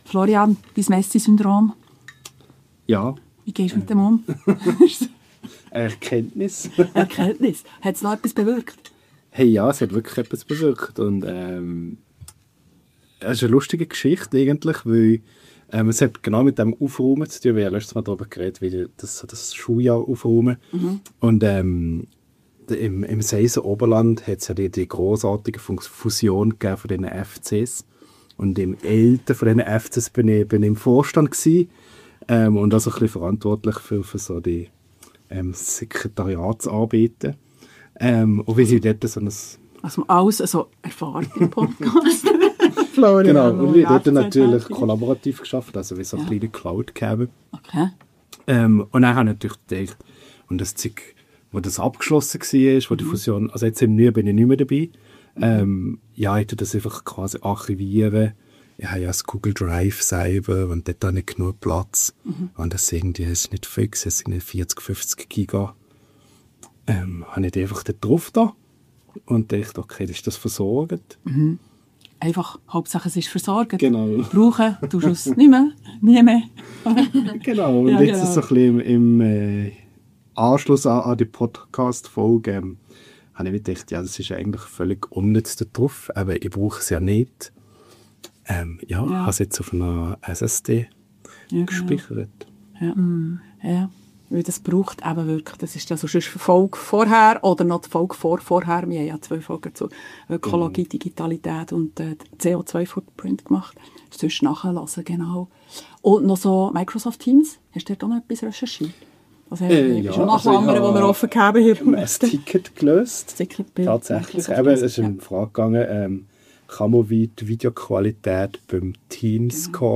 Ein Gespräch zwischen Didaktik, Philosophie, Alltagsgeschichten – und sehr viel Leidenschaft für Bildung.